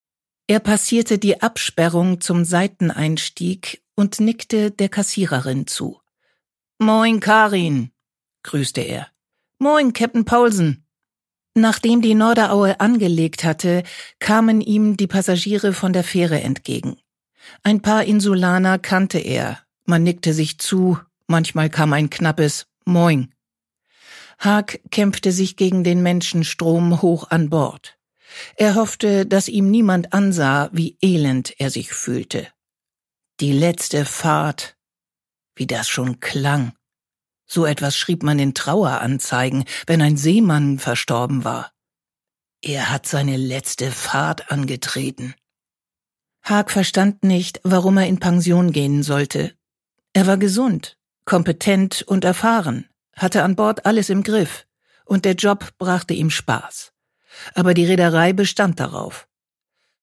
Produkttyp: Hörbuch-Download
Gelesen von: Sabine Kaack
Charmant, lebendig und frisch wie eine Nordseebrise liest Sabine Kaack diesen Inselroman.